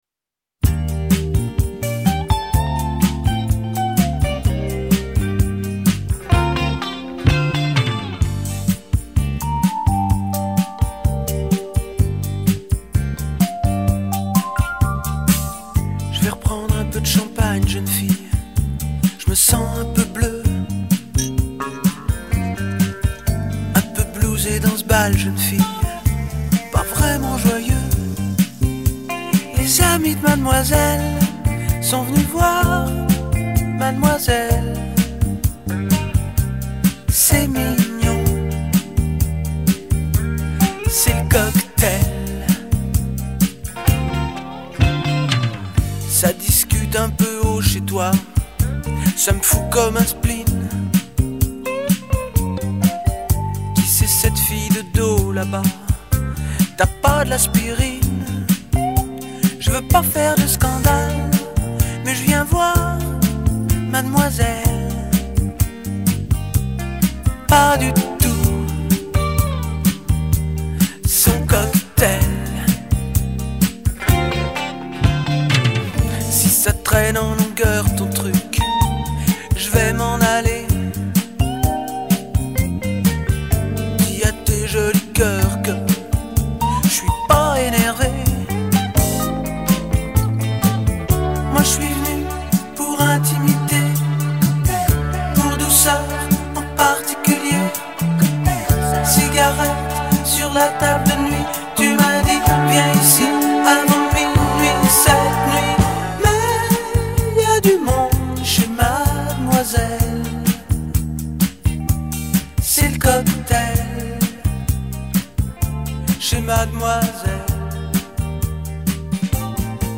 balade jazz-cool
guitares et Fender Rhodes…